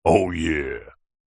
Звуки речи, голоса, пения
Мужчина средних лет рассуждает о да